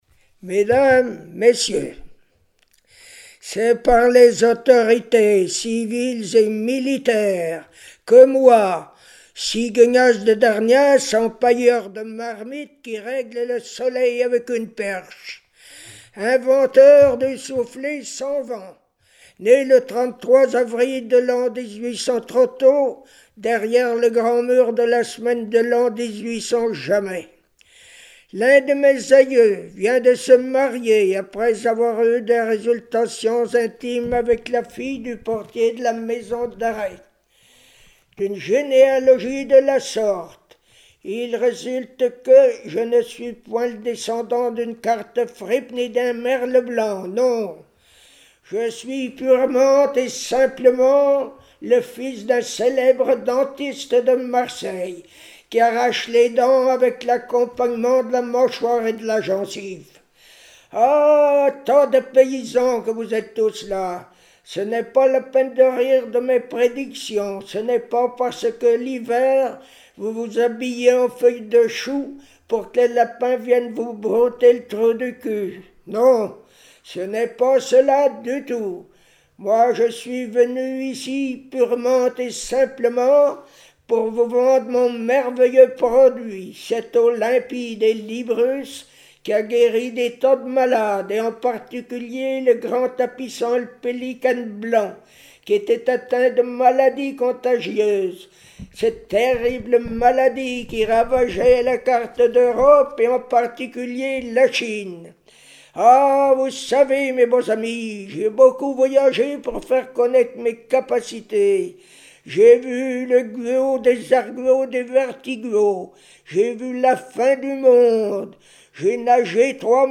Genre sketch
Catégorie Récit